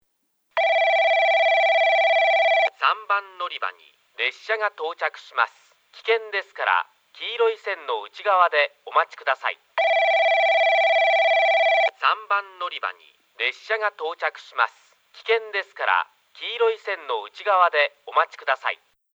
3番のりば　接近放送　男声   放送はJACROS簡易型です。
スピーカーはJVCラインアレイとTOAラッパ型、UNI‐PEXラッパ型です。